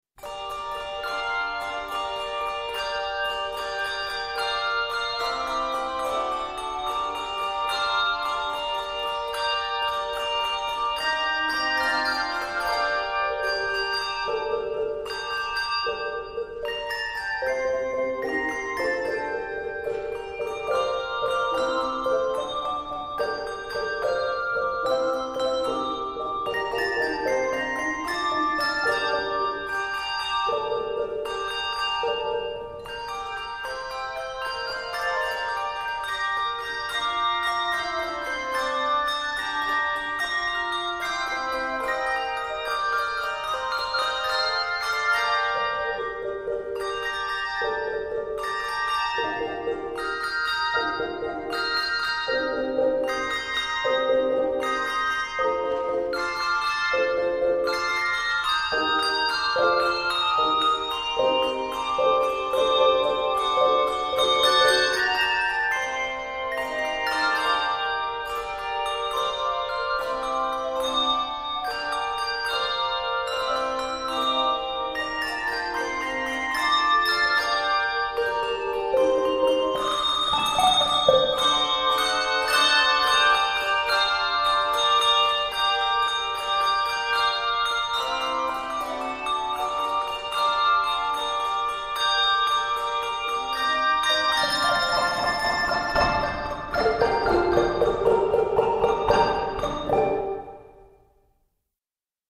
Handbells Level